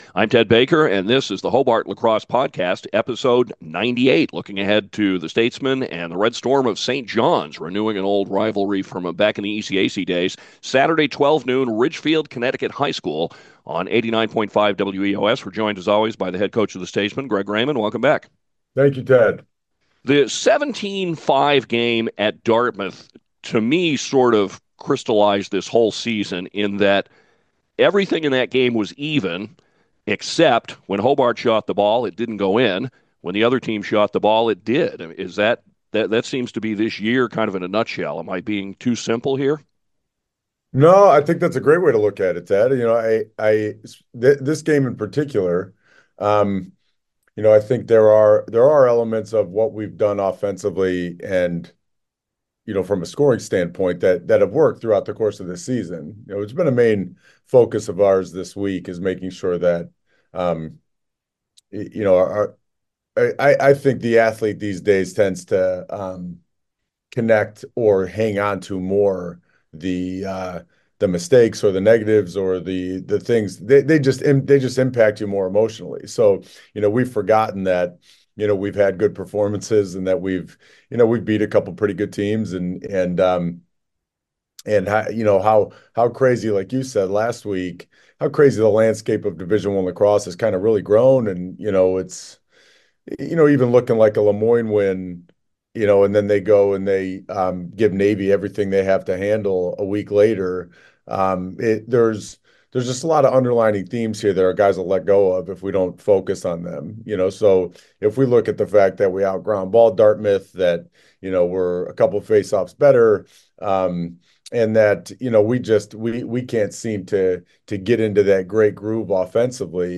The Hobart Lacrosse Podcast is recorded before each game during the season and monthly during the offseason.